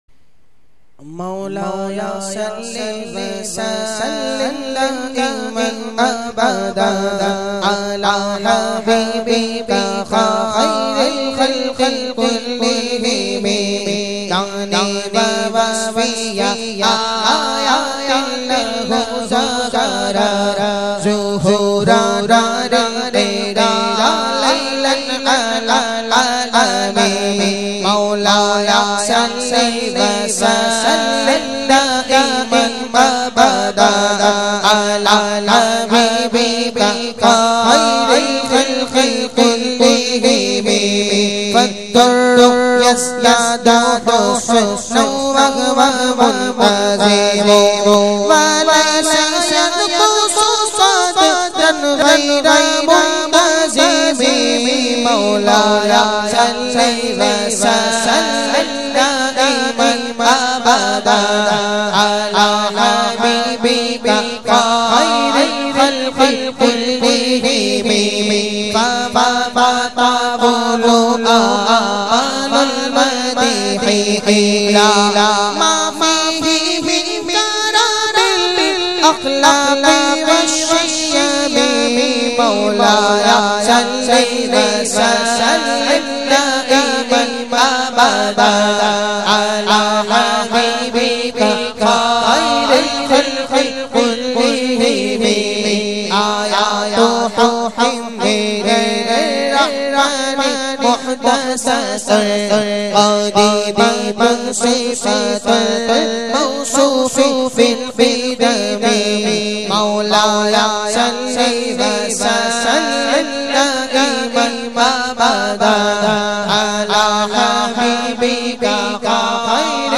recited by famous Naat Khawan